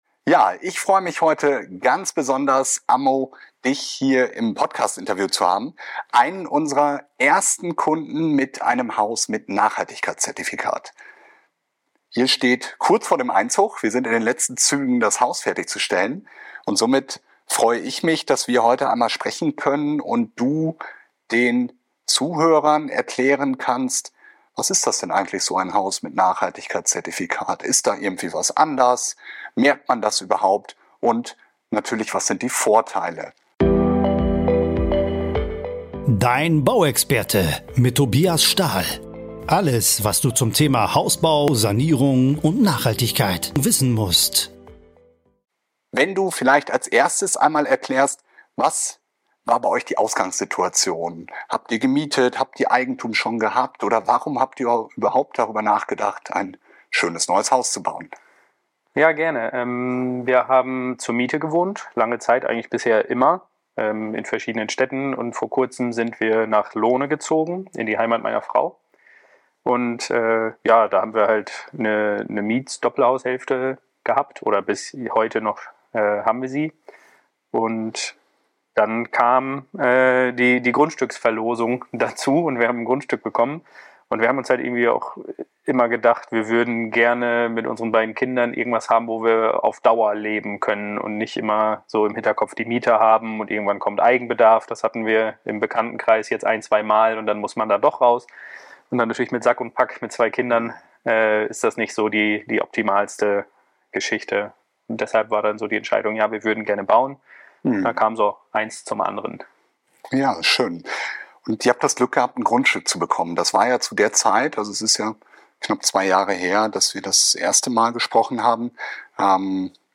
Wie ist es, wenn man mit kleinen Kindern ein Haus baut, beide Eltern berufstätig sind und man sich auch noch für das QNG Zertifikat entscheidet? Warum es gerade in so einem Fall so sinnvoll ist schlüsselfertig zu bauen, erfahrt ihr in diesem Podcastinterview.